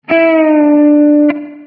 Podciągamy strunę i dopiero wtedy gramy dźwięk (podciągnięty), a następnie odpuszczamy.
Jak już wyjdzie to otrzymamy płynny zjazd w dół: